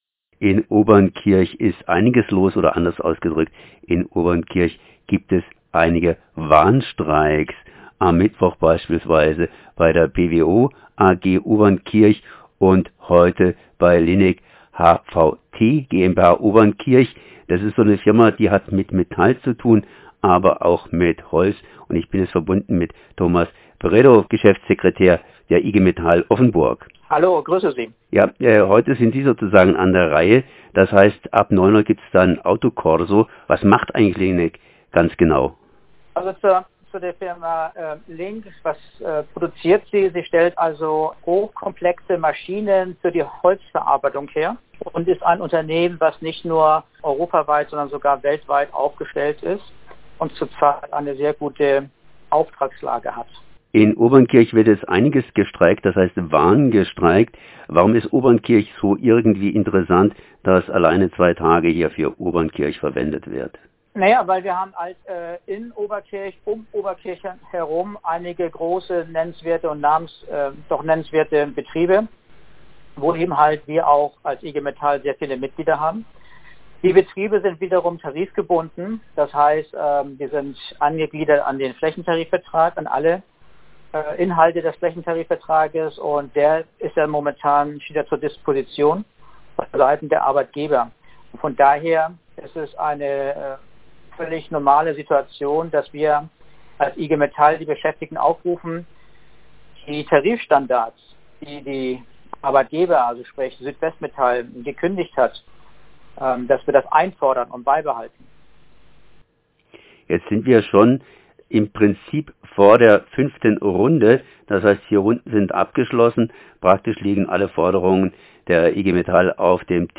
In Oberkirch organisiert die Igmetall heute ihren zweiten Warnstreik. Ein Gespräch